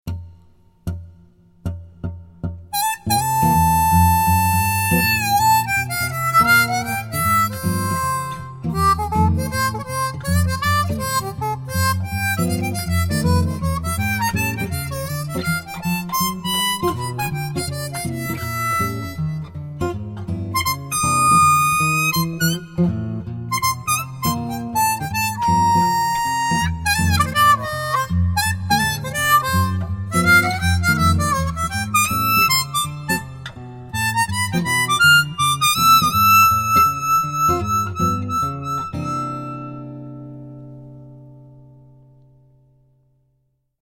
overblow2.mp3